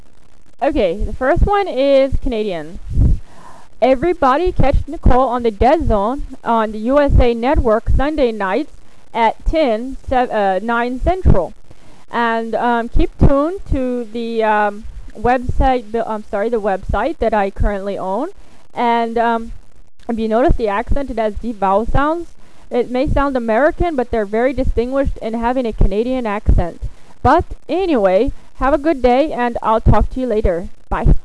Here are a list of Accents and Impersonations I can do...
Canada
canadianii.wav